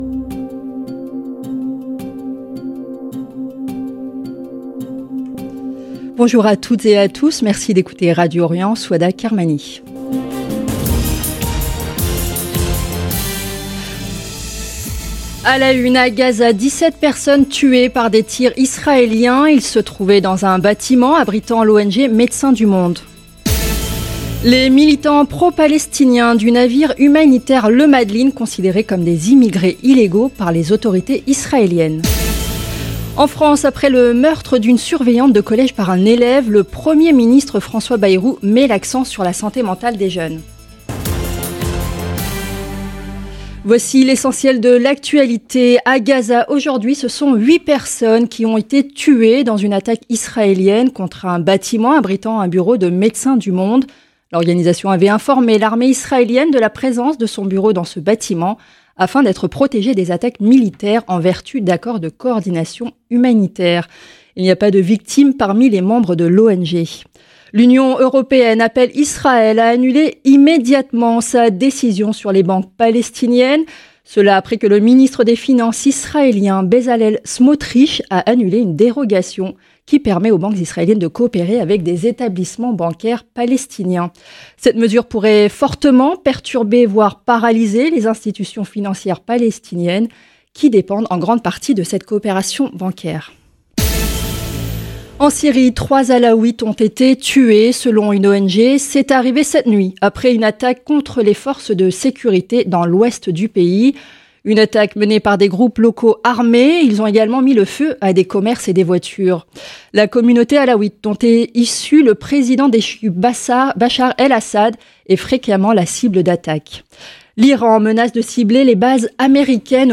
Magazine de l'information de 17 H00 du mercredi 11 juin 2025